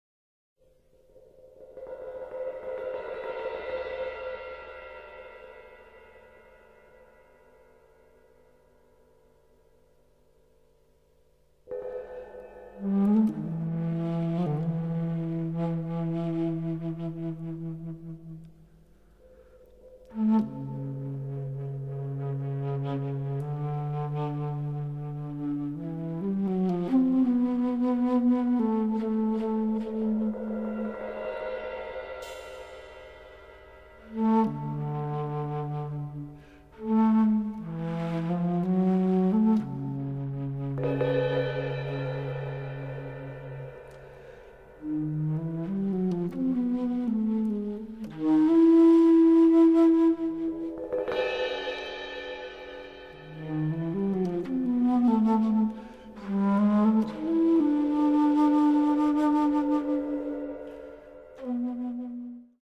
at studio Voice
バス・フルート
フルート
ハチノスギ太鼓(創作楽器)
竹琴(自作楽器)
タム